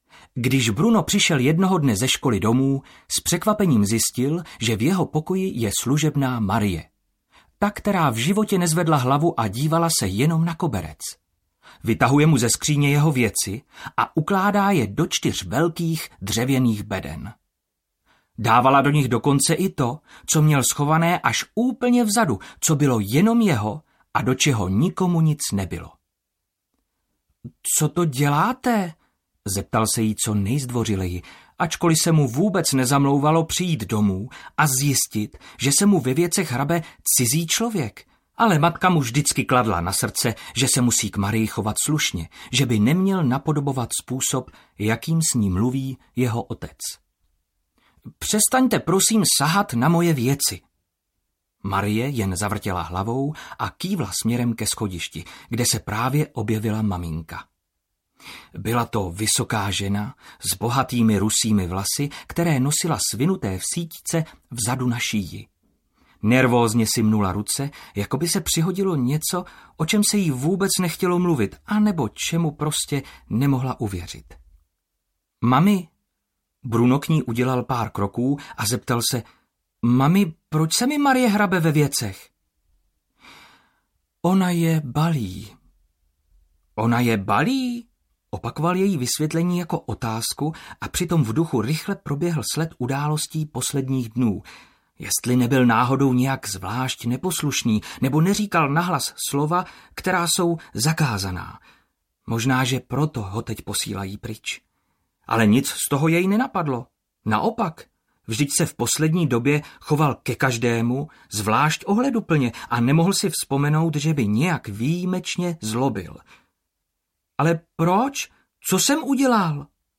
Chlapec v pruhovaném pyžamu audiokniha
Ukázka z knihy